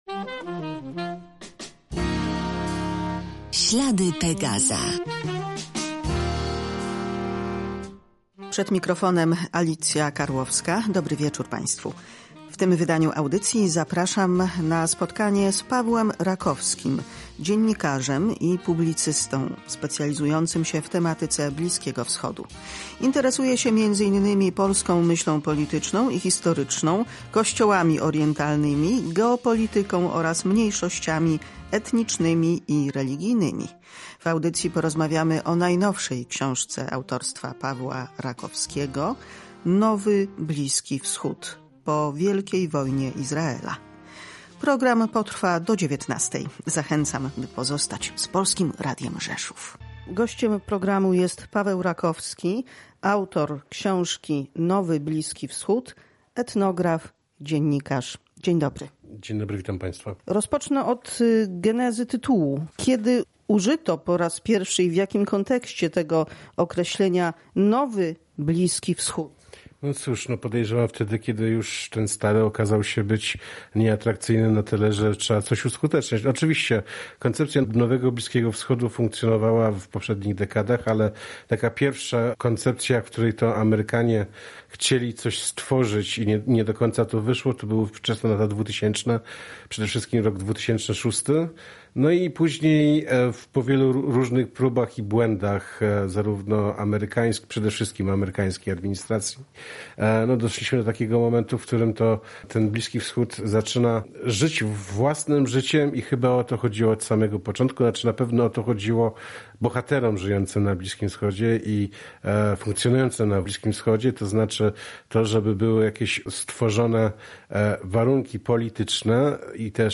Rozmowa o publikacji oraz kulisy wydarzeń bliskowschodnich.